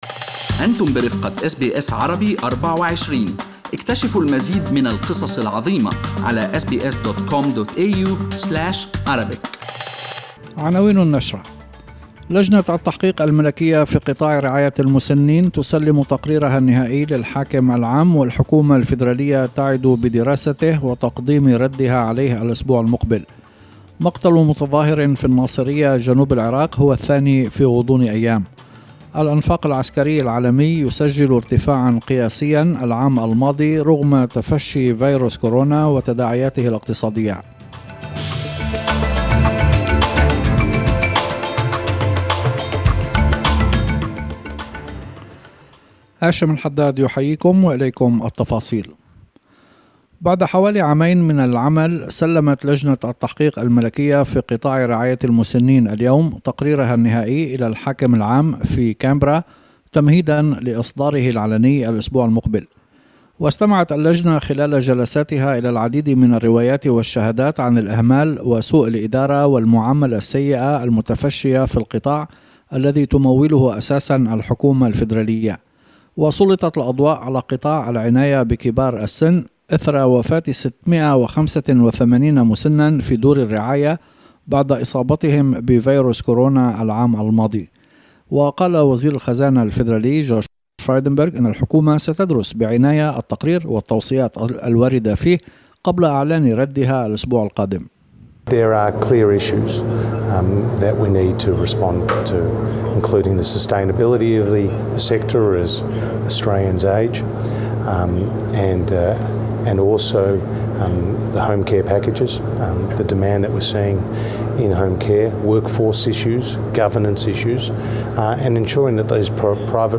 نشرة أخبار المساء 26/02/2021